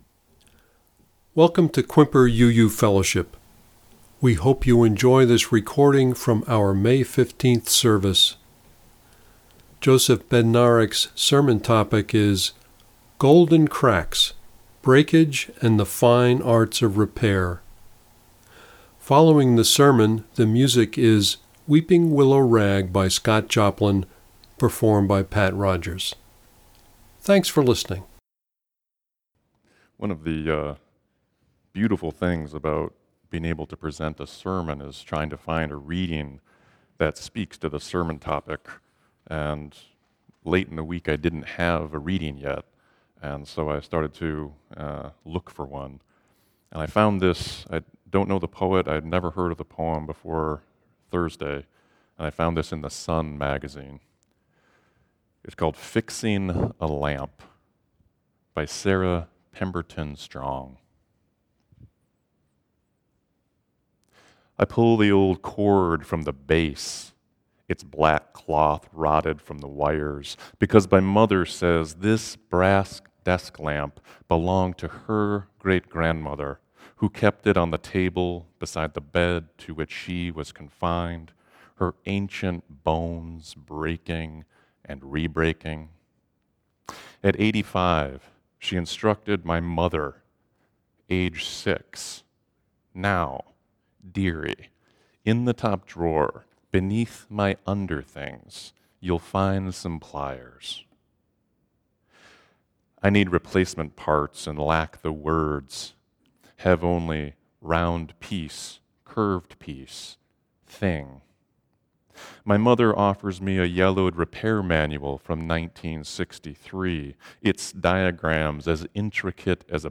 Listen to the reading and sermon.